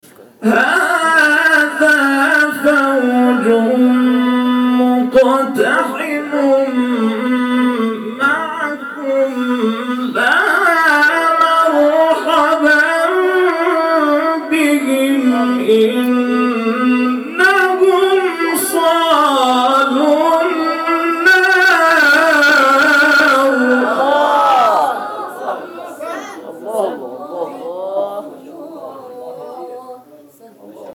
گروه شبکه اجتماعی: مقاطعی از تلاوت‌های صوتی قاریان برجسته کشور ارائه می‌شود.